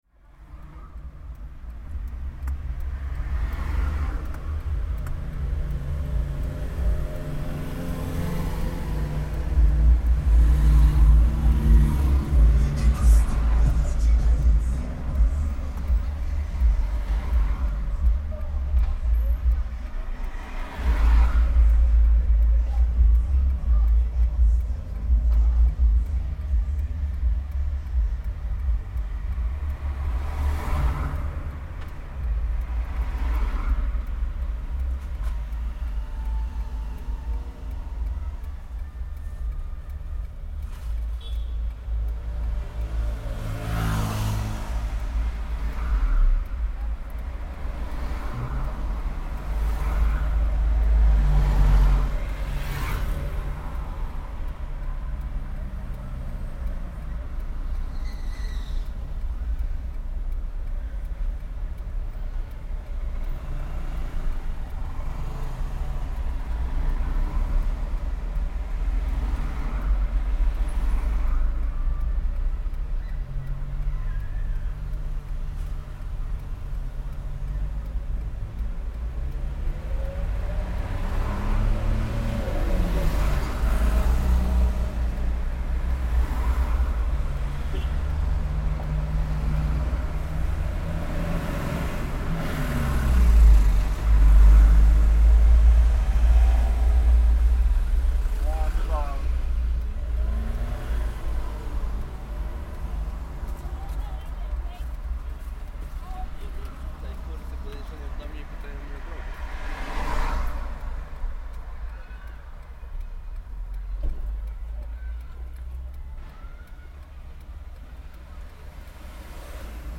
Waiting at the car
Sitting in a car, waiting for something, anything, and listening to the traffic rolling by.
You can hear the blinker / turn signal “beep beeping” on the foreground. The rolling of the tires, music from the passing car windows, polite car horns and people passing by ect make an surprisingly hi-fi soundscape.